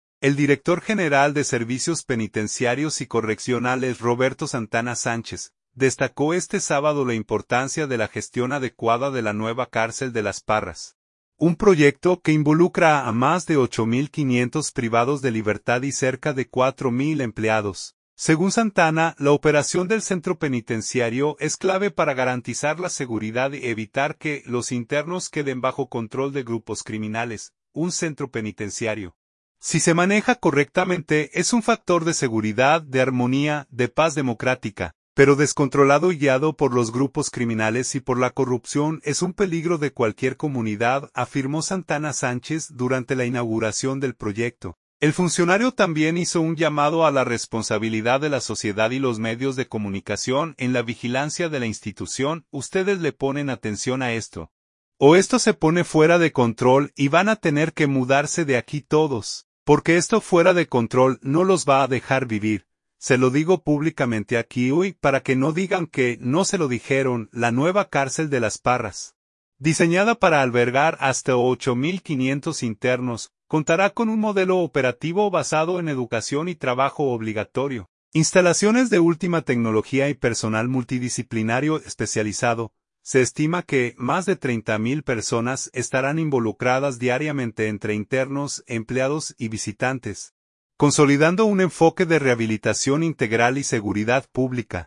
“Un centro penitenciario, si se maneja correctamente, es un factor de seguridad, de armonía, de paz democrática, pero descontrolado y guiado por los grupos criminales y por la corrupción, es un peligro de cualquier comunidad”, afirmó Santana Sánchez durante la inauguración del proyecto.